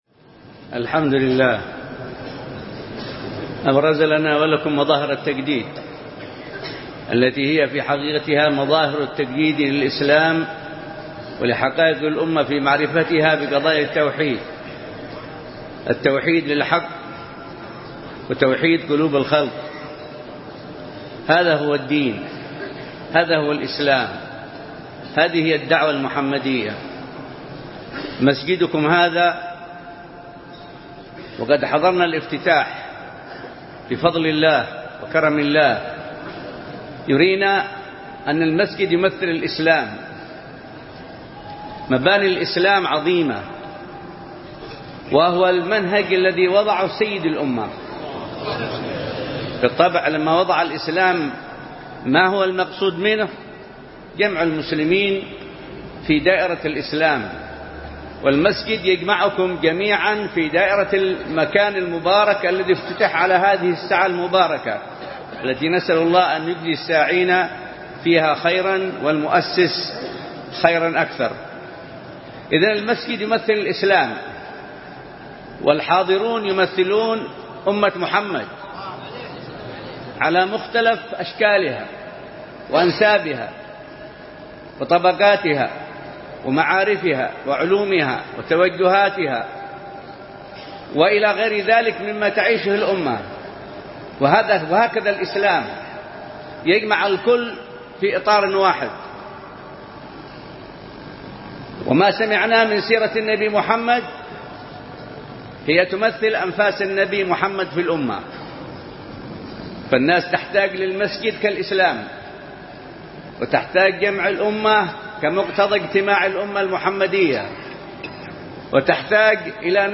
كلمة
في جامع مسجد تاربة